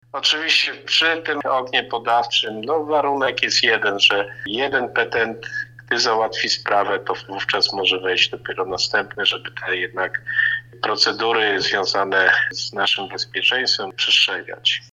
– Pamiętajmy jednak o zachowaniu podstawowych środków ostrożności – apeluje wójt Leszek Surdy.